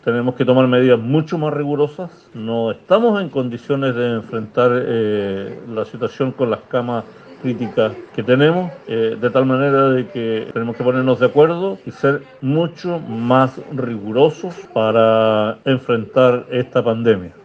El alcalde de San Pedro de la Paz y además presidente de la Asociación de Municipios del Bío Bío, Audito Retamal, también insistió en que se necesitan medidas que sean más estrictas para controlar el avance de la enfermedad.
cua-salud-alcalde-san-pedro.mp3